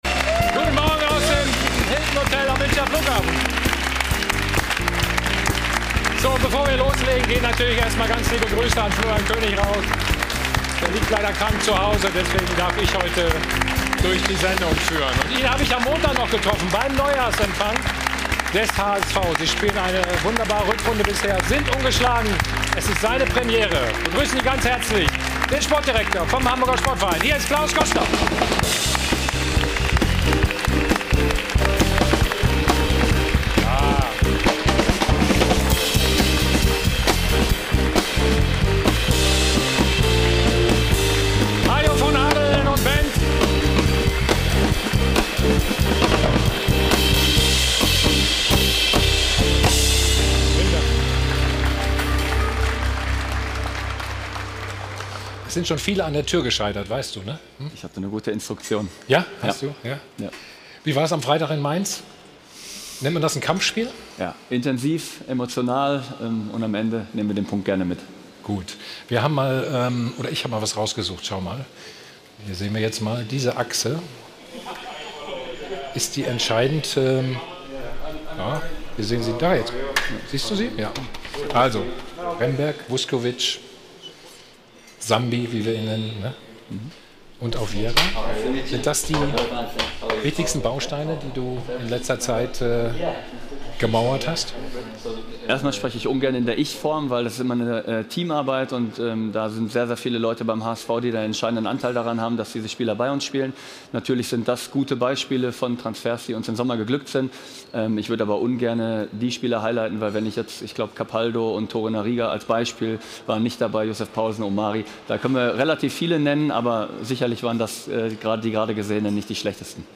Aufgrund technischer Probleme fehlen einzelne Ausschnitte der Sendung.